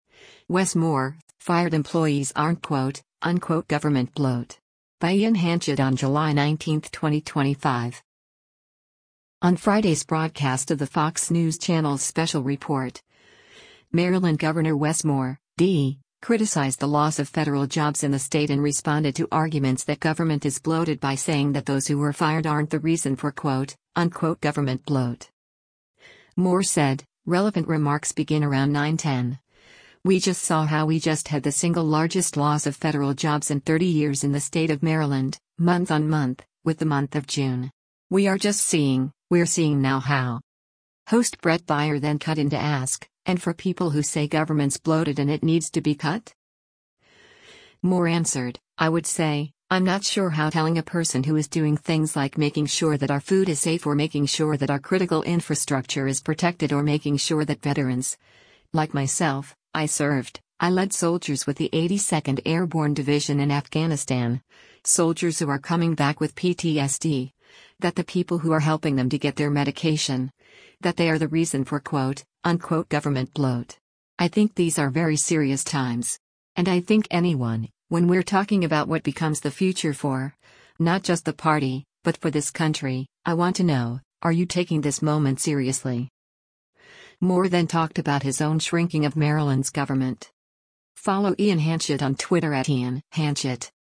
On Friday’s broadcast of the Fox News Channel’s “Special Report,” Maryland Gov. Wes Moore (D) criticized the loss of federal jobs in the state and responded to arguments that government is bloated by saying that those who were fired aren’t “the reason for quote — unquote government bloat.”
Host Bret Baier then cut in to ask, “And for people who say government’s bloated and it needs to be cut?”